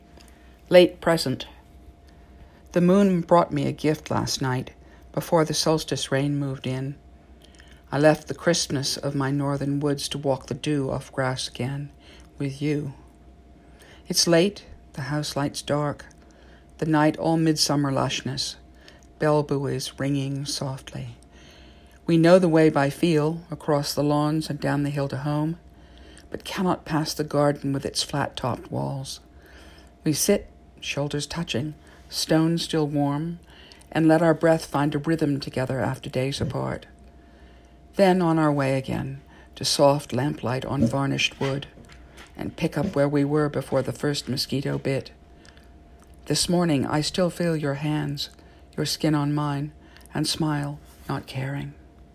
Audio: Read by the author.